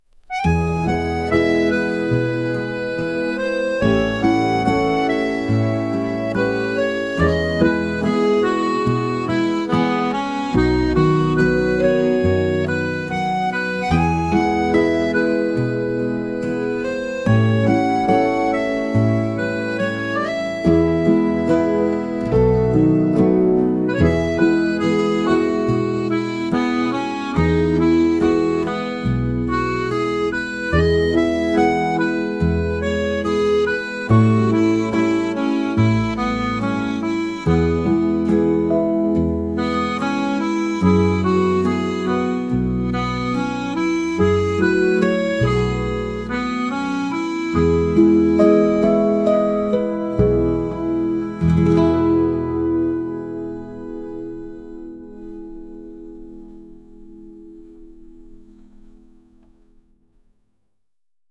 ゆったりとまどろむようななアコーディオン曲です。